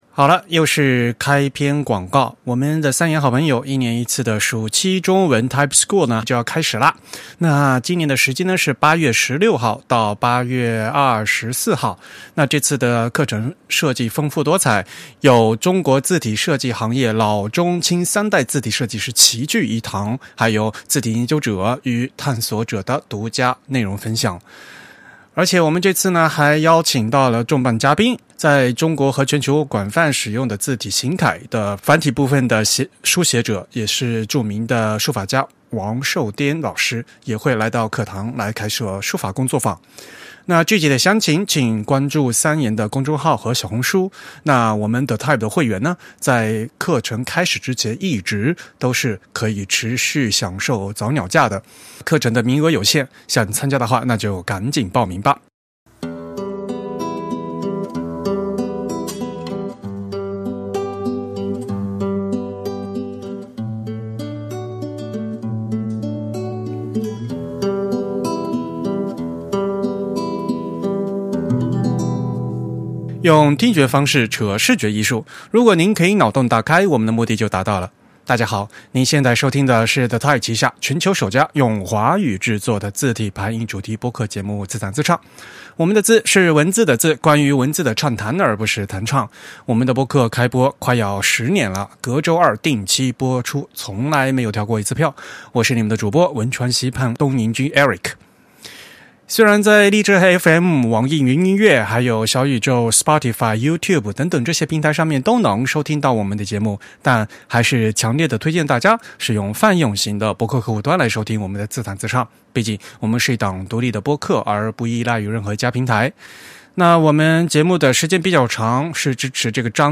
ATypI 2025 系列访谈第七期